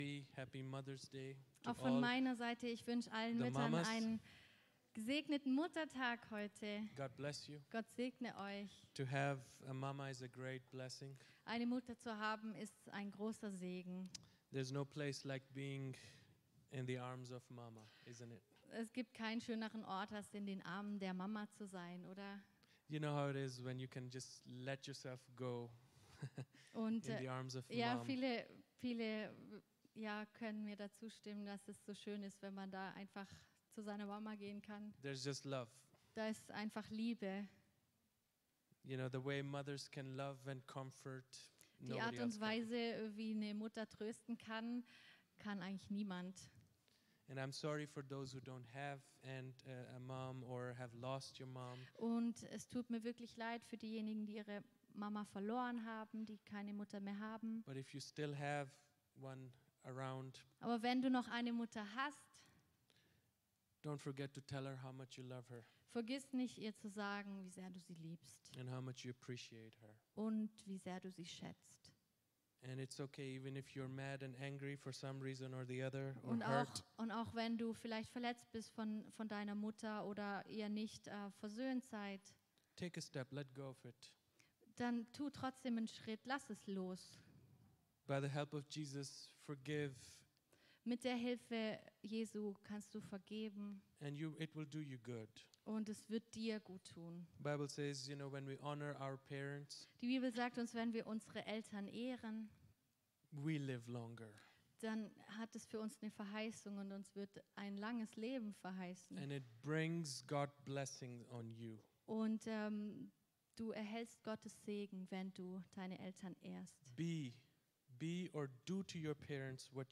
Predigt
im Christlichen Zentrum Villingen-Schwenningen.